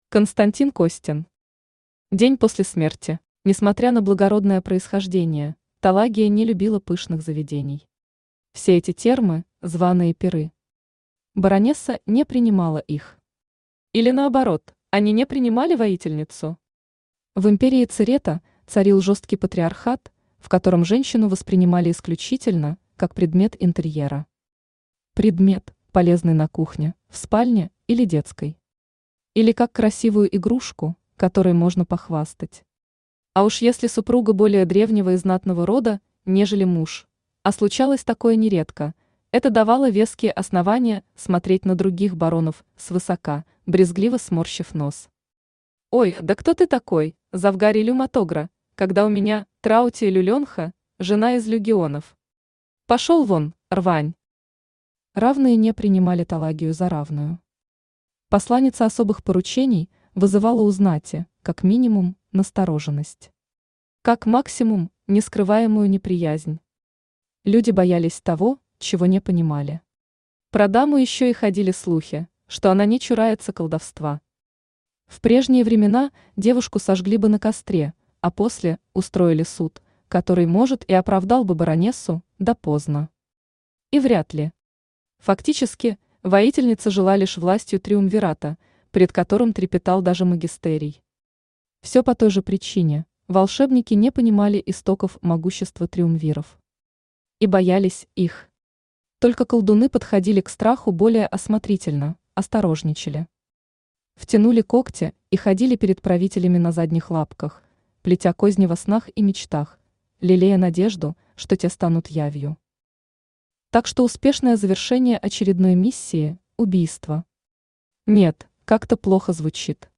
Аудиокнига День после смерти | Библиотека аудиокниг
Aудиокнига День после смерти Автор Константин Александрович Костин Читает аудиокнигу Авточтец ЛитРес.